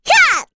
Rehartna-Vox_Attack3_kr.wav